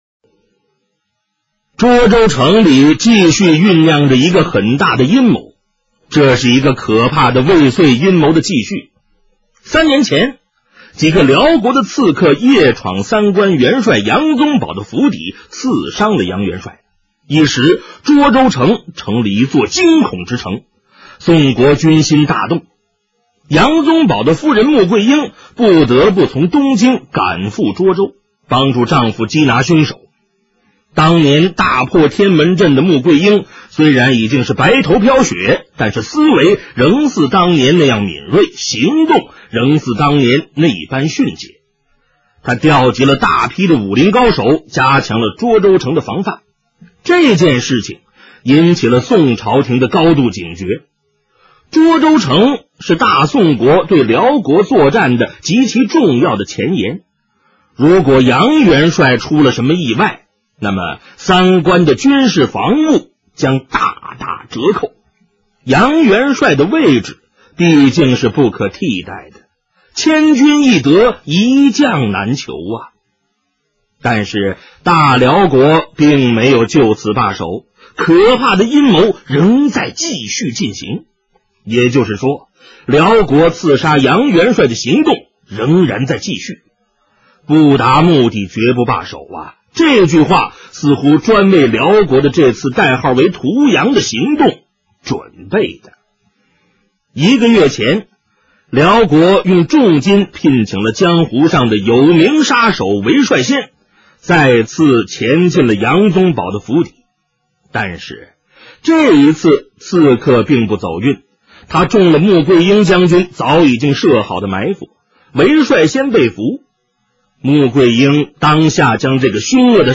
【评书欣赏】《新白玉堂传奇》